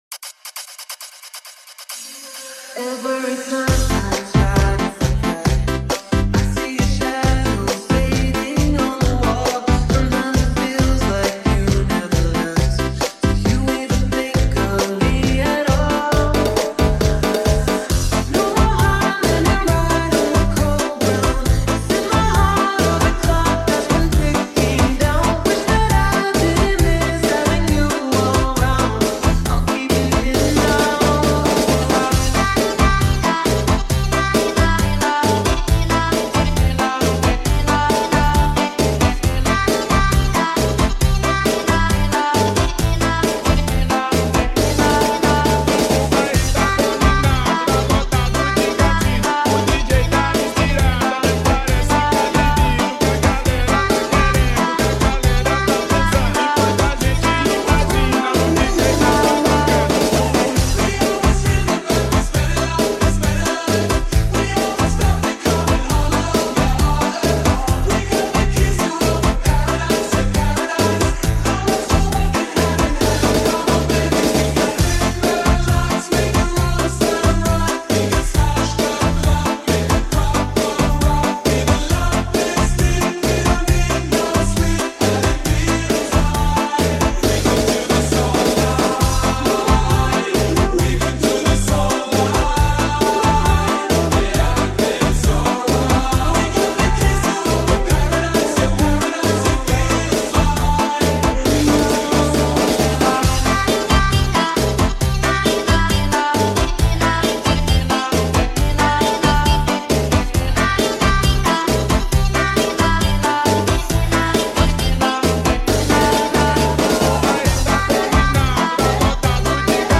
THAI STYLE